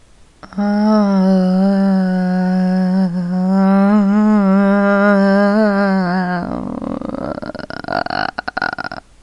僵尸 " 僵尸死亡2
描述：一个僵尸死了
标签： 嘶嘶声 呻吟 咆哮 僵尸 令人毛骨悚然 恐怖 轰鸣声 呻吟声 咆哮 亡灵
声道立体声